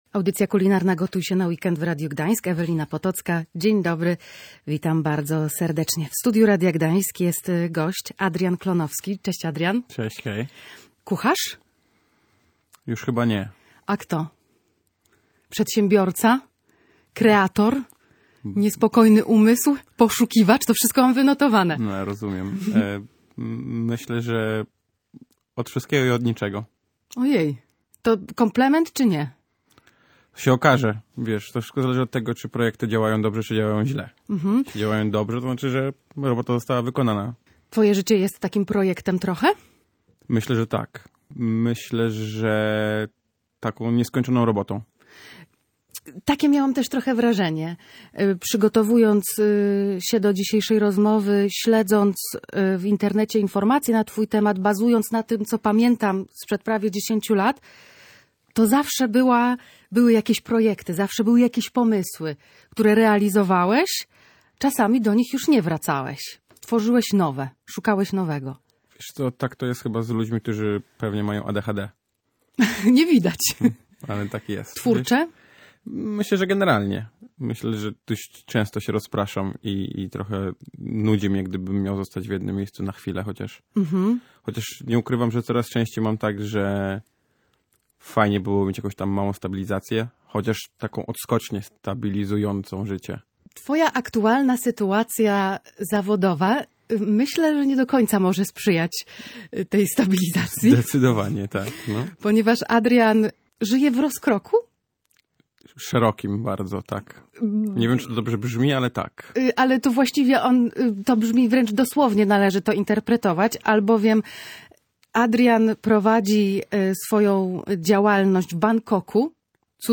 O swojej pasji i sposobie na życie opowiedział na naszej antenie w audycji „Gotuj się na Weekend”.